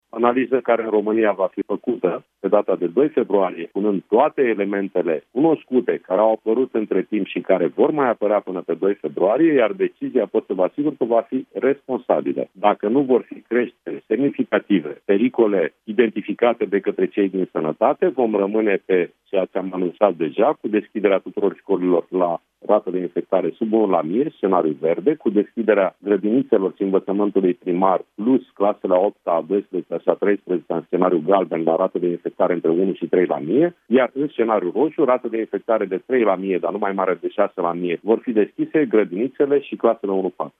În acest context, ministrul Educației precizează că școlile vor fi deschise de la 8 februarie, doar dacă situația epidemiologică nu se va agrava.